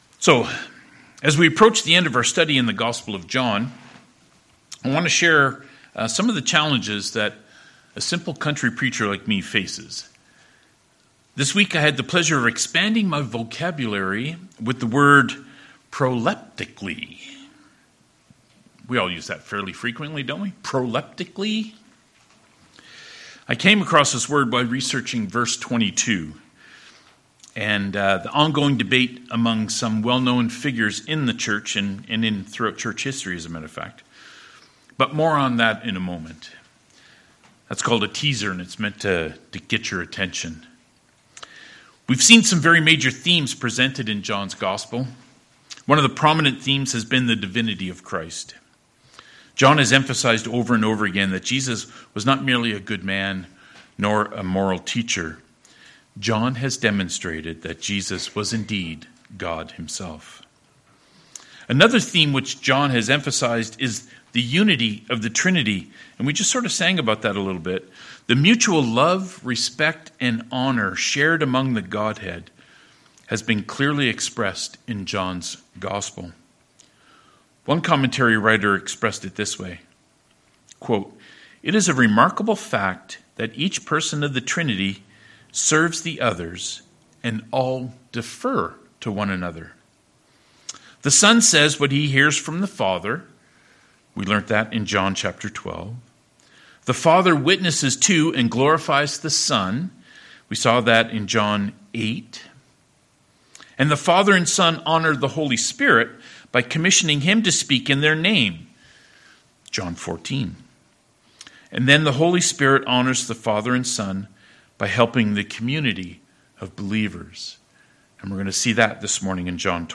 John 20:19-23 Service Type: Sermons « The Resurrection of Christ What’s Holding You Back?